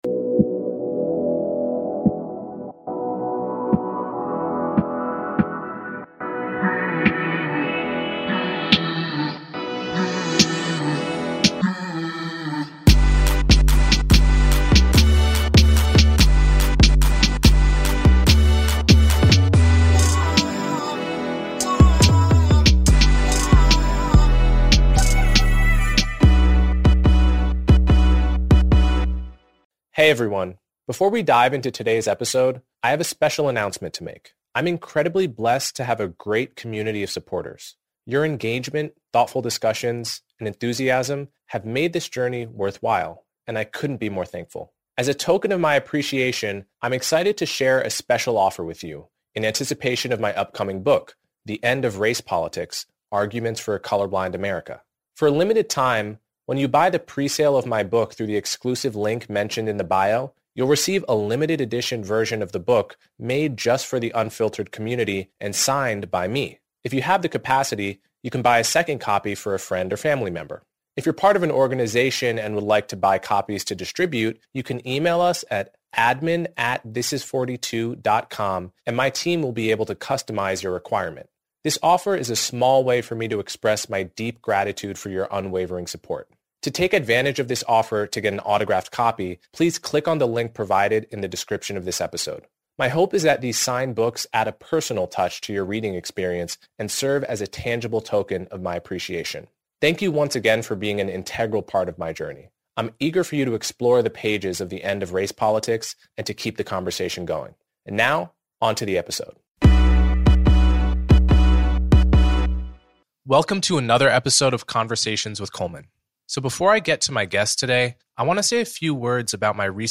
My guest today is Rory Stewart.